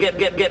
Hip-Hop Vocals Samples